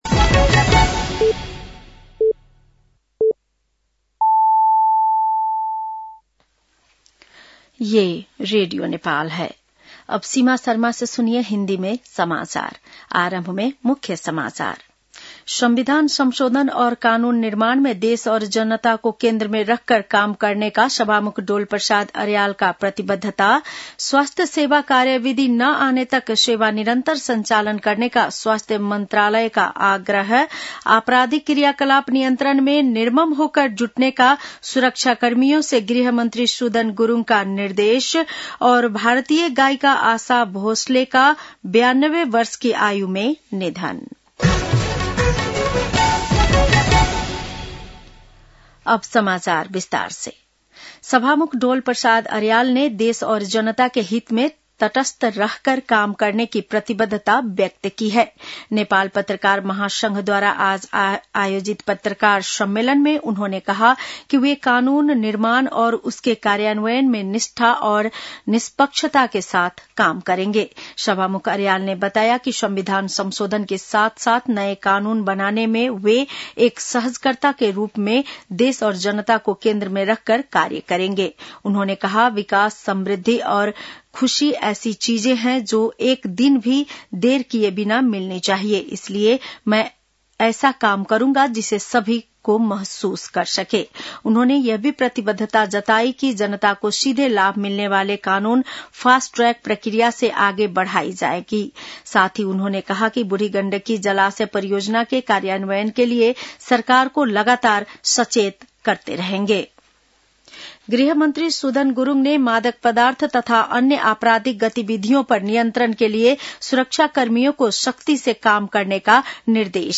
बेलुकी १० बजेको हिन्दी समाचार : २९ चैत , २०८२